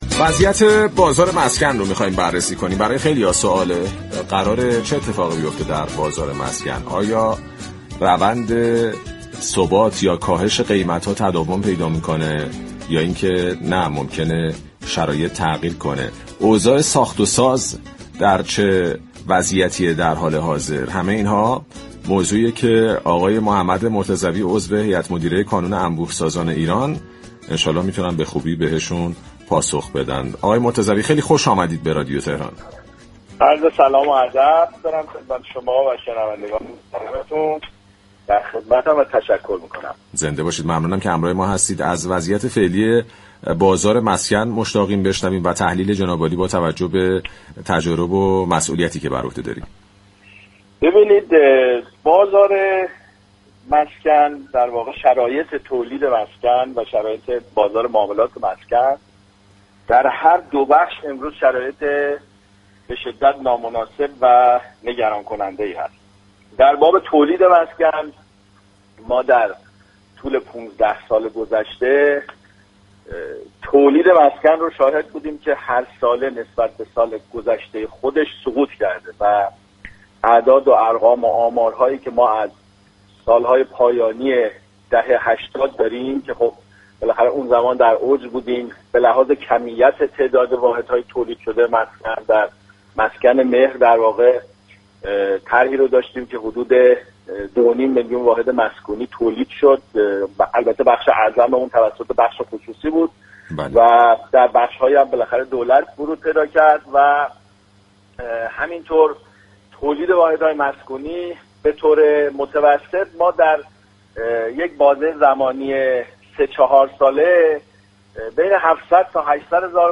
عضو هیئت مدیره كانون انبوه‌سازان ایران در گفت‌وگو با رادیو تهران با اشاره به كاهش تولید مسكن و محدودیت توان خرید، تاكید كرد كه بسته‌های حمایتی دولت و مشاركت بخش خصوصی می‌تواند مسیر ثبات و رونق بازار را هموار كند.